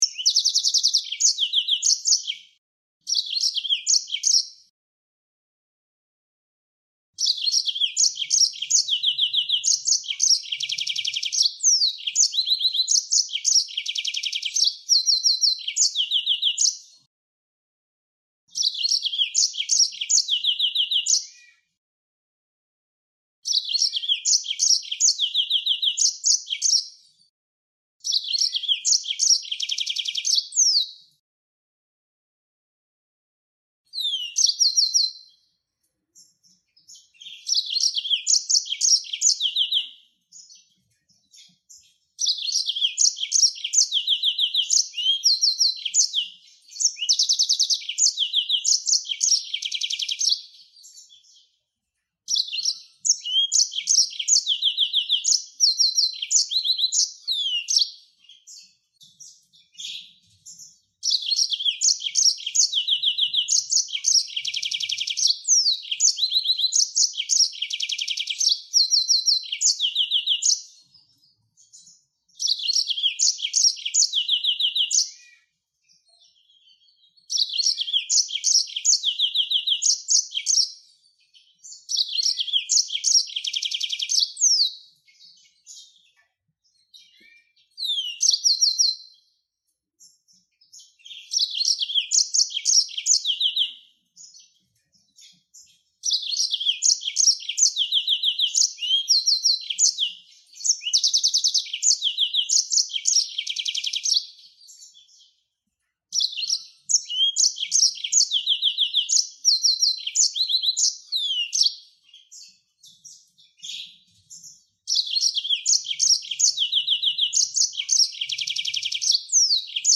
Mozambik Kuşu Ötüşü
serinus-mozambicus-.mp3